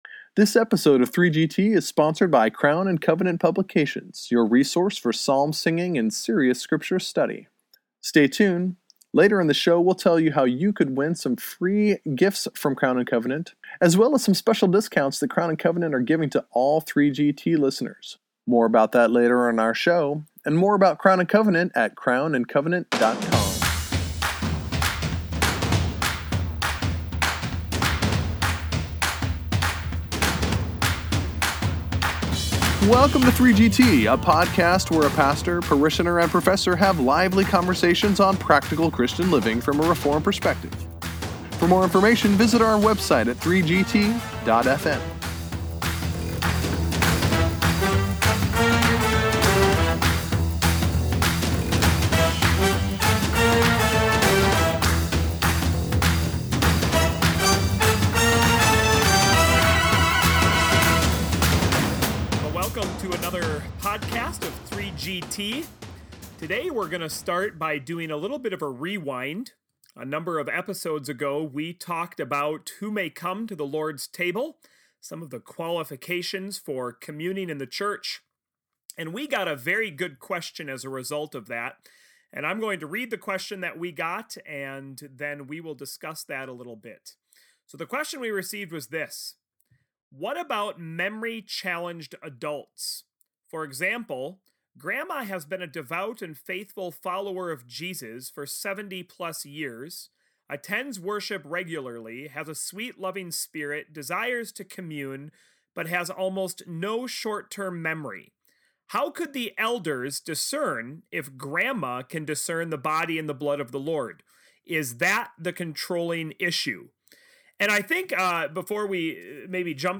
The 3GTers go back in time to pick up on their discussion about the Lord’s Table from Episode 23.